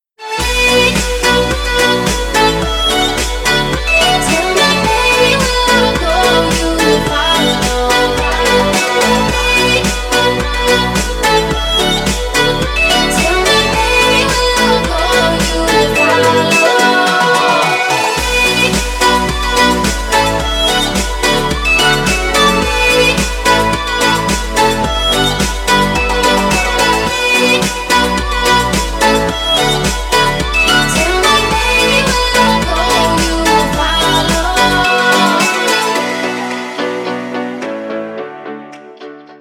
• Качество: 320, Stereo
dance
club